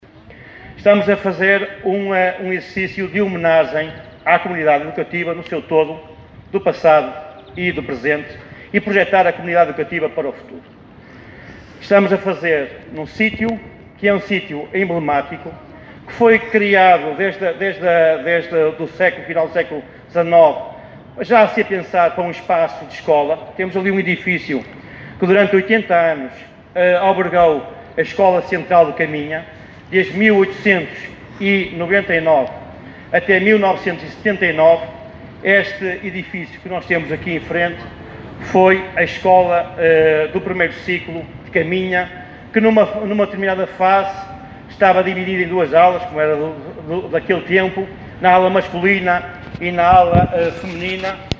Foi inaugurada ontem, no Largo Sidínio Pais em Caminha, a escultura SCHOLA, um marco que presta homenagem à comunidade escolar do concelho, passada e presente.
O local que recebeu a SCHOLA é emblemático, uma vez que aí funcionou durante 80 anos a Escola Central de Caminha, como sublinhou o presidente da Junta de Freguesia, Miguel Gonçalves.